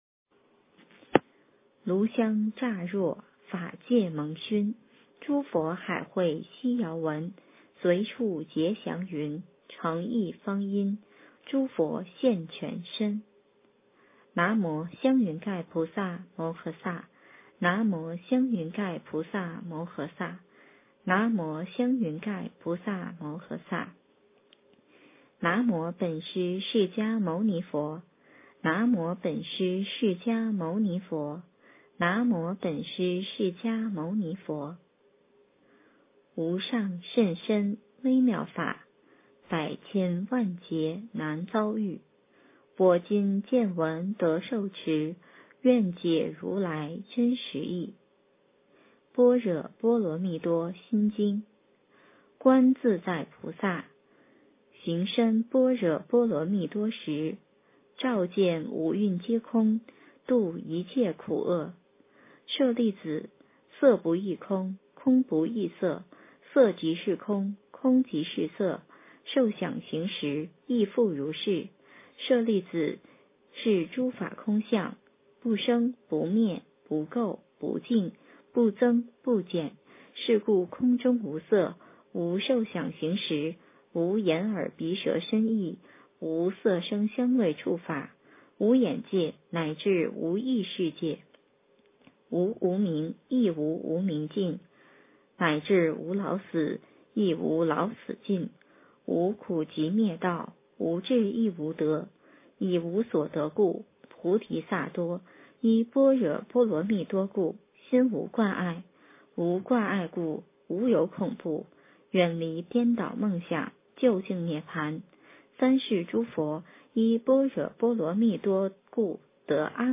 诵经
佛音 诵经 佛教音乐 返回列表 上一篇： 心经 下一篇： 大悲咒 相关文章 大慈大悲观世音菩萨 大慈大悲观世音菩萨--孙露...